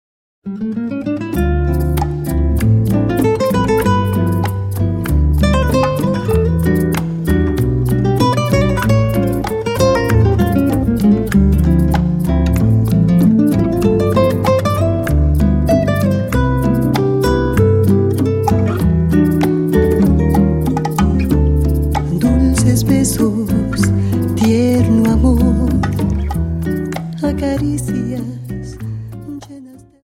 Dance: Rumba Song